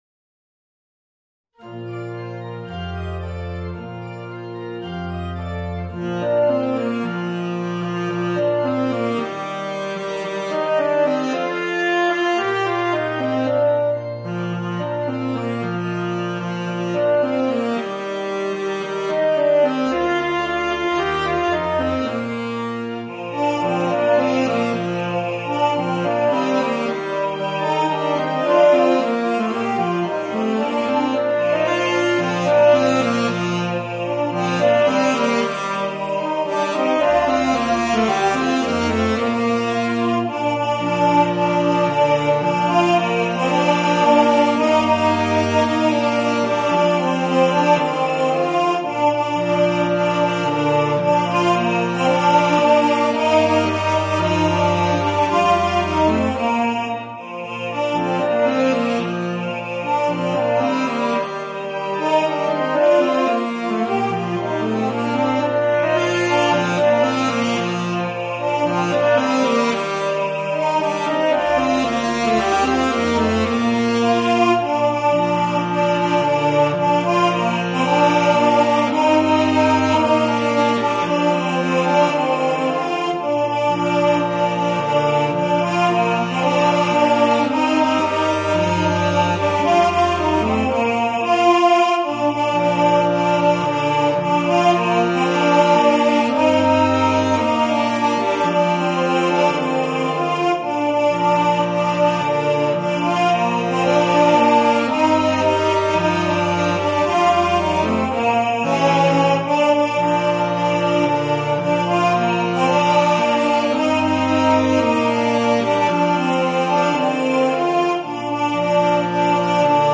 Voicing: Tenor Saxophone, Baritone